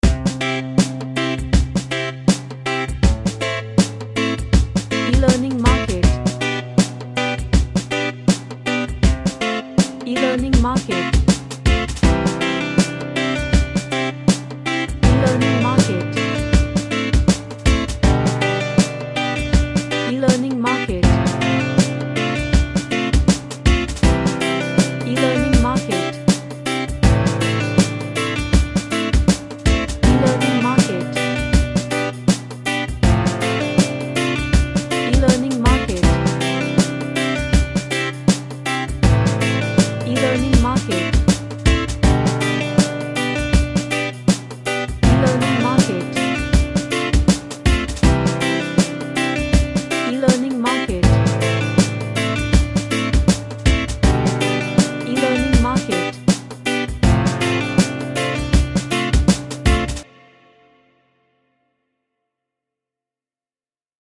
A Happy reggae track with lots of energy.
Happy